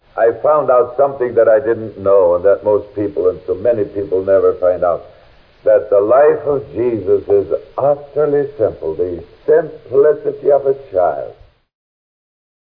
Audio Quality: Fair